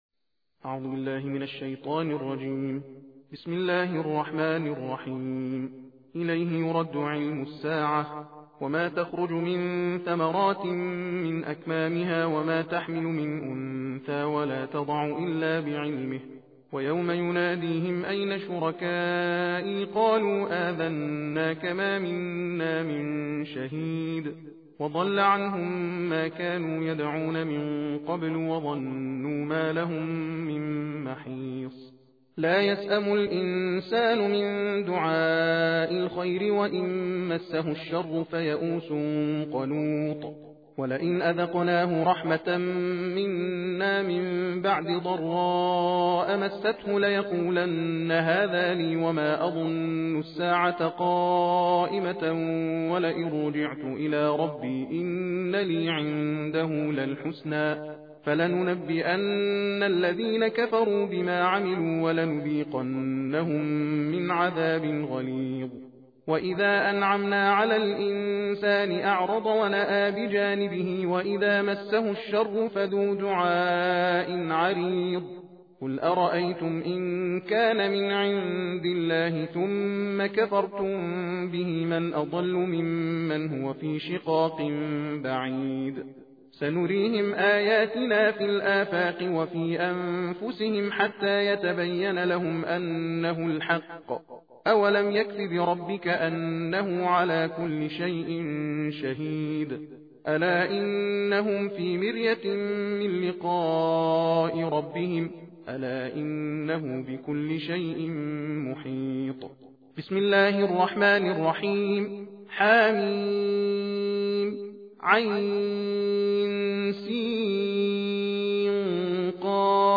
🎙 تحدیر جز بیست و پنجم قرآن کریم…
تند خوانی